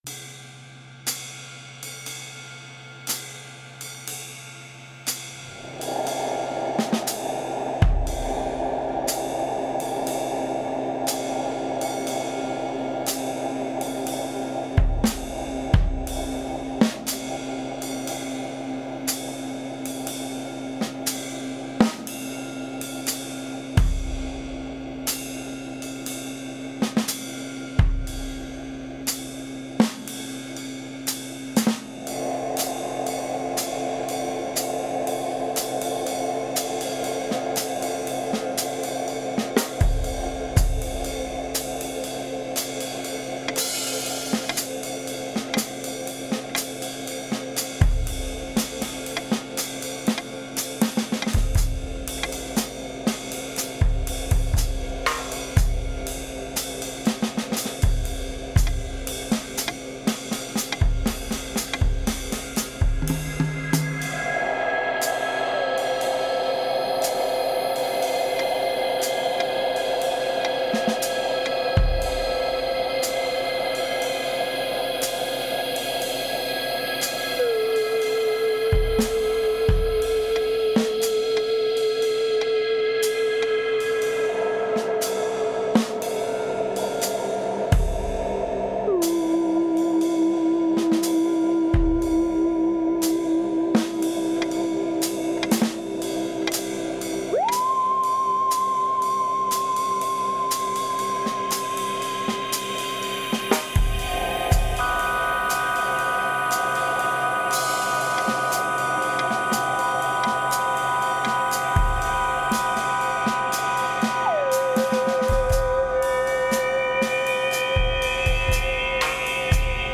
experimental ambient and electronic music works